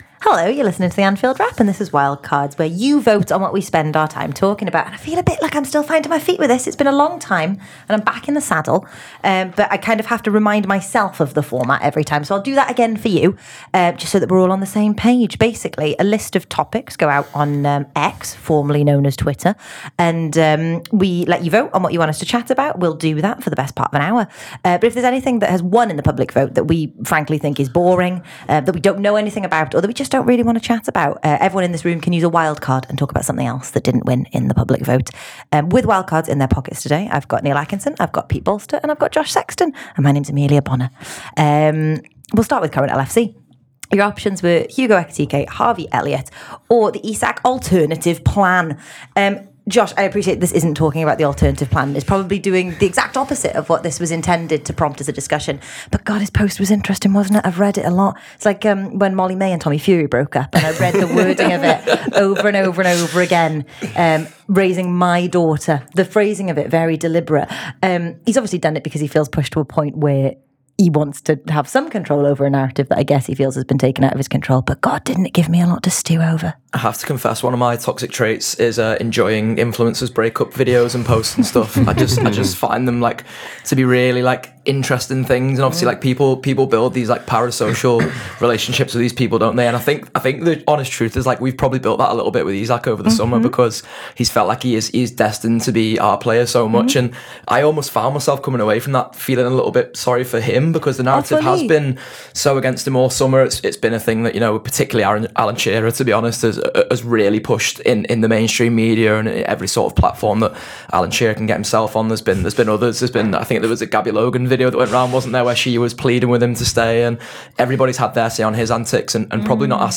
Below is a clip from the show – subscribe for more on the popular topics of the week…